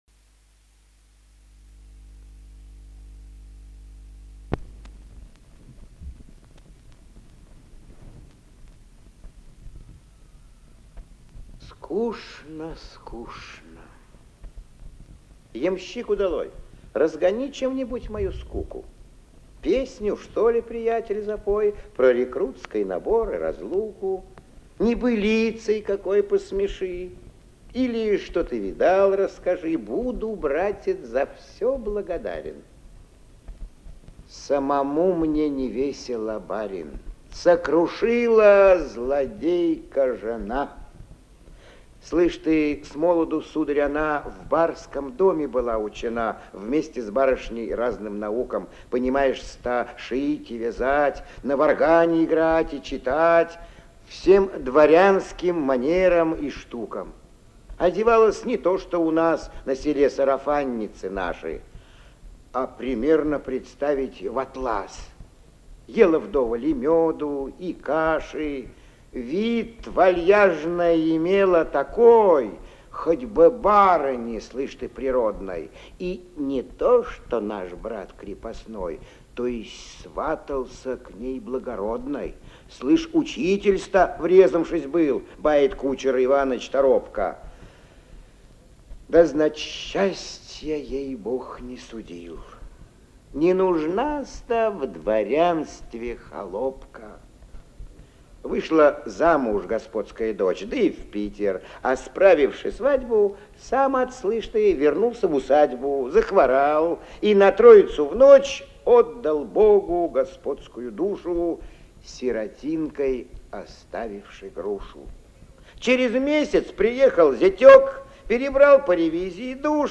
4. «Н.А. Некрасов – В дороге (читает А. Грибов) Страницы русской поэзии XVIII-XX веков» /